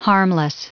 Prononciation du mot harmless en anglais (fichier audio)
Prononciation du mot : harmless